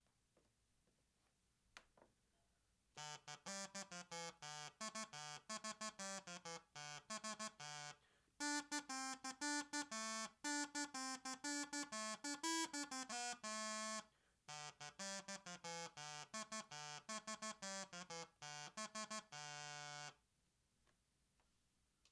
Crumhorn by the Susato Workshop The crumhorn is a woodwind instrument.
So, like the recorder, the crumhorn has a very limited dynamic range.
crumhornsample1.wav